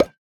Minecraft Version Minecraft Version latest Latest Release | Latest Snapshot latest / assets / minecraft / sounds / block / decorated_pot / insert3.ogg Compare With Compare With Latest Release | Latest Snapshot